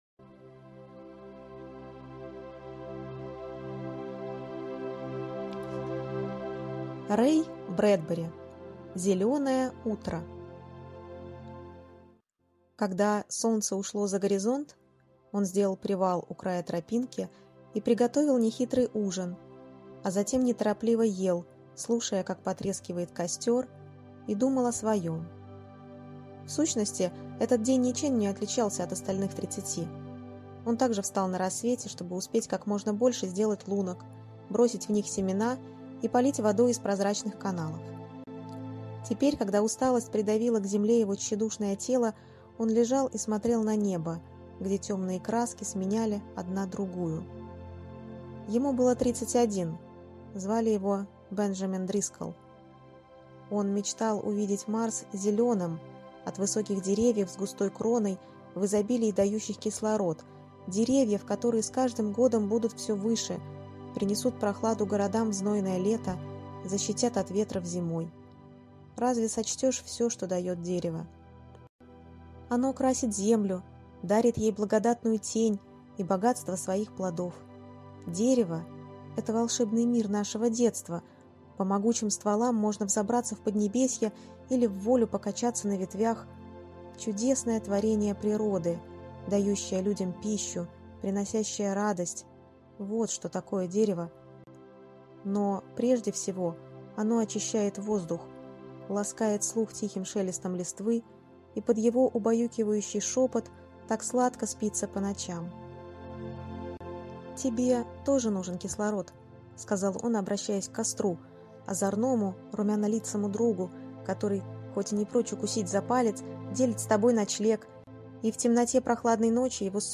Зеленое утро - аудио рассказ Брэдбери Р. Рассказ про Бенджамина Дрисколла, прилетевшего на Марс и столкнувшегося с проблемой нехватки воздуха.